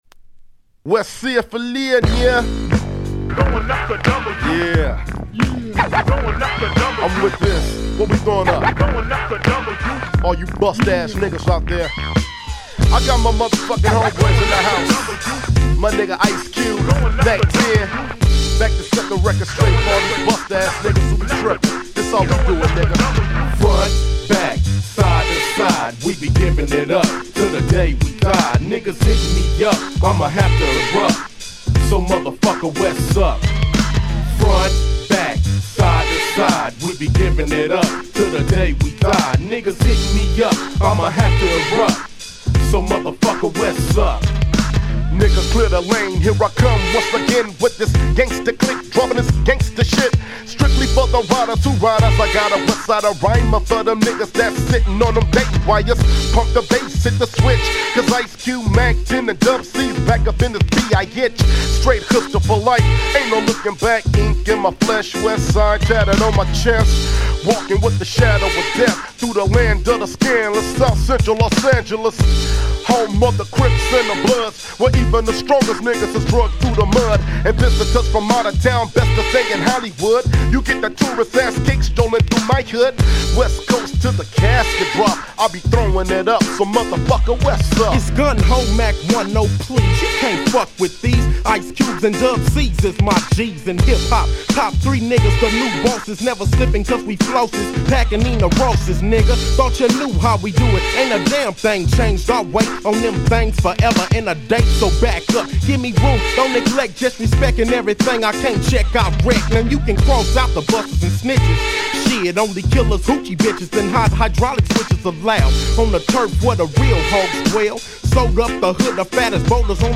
95' Smash Hit West Coast Hip Hop !!